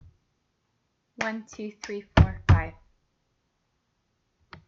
Creating a Rhythm with Body Percussion
5 — Clap, shoulder tap, shoulder tap, right stomp, left stomp
Rhythm-Game-5.m4a